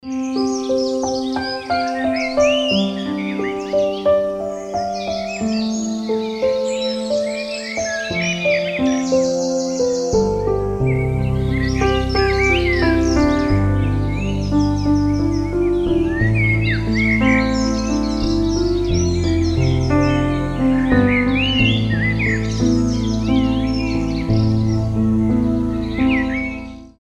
Звуки природы , Звуки животных
Пение птиц , Инструментальные